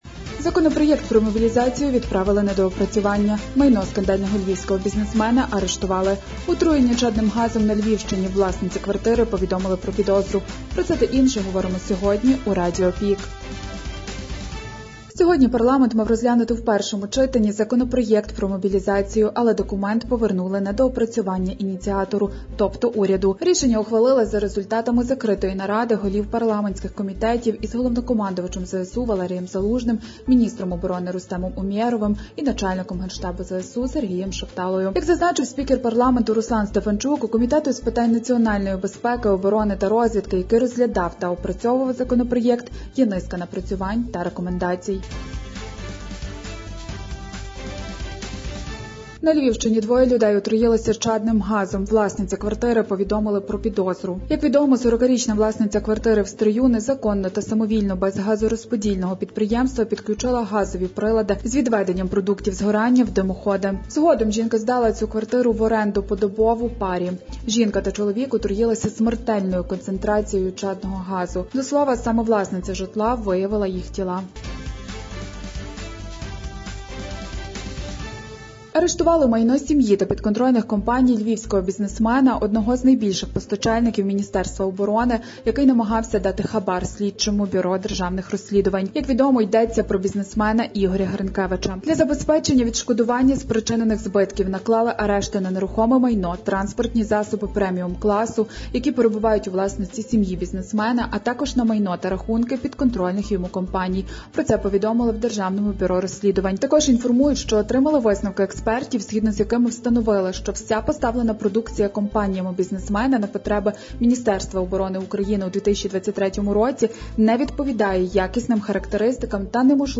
Радіо ПІК: головні новини Прикарпаття та України за 11 січня (ПРОСЛУХАТИ)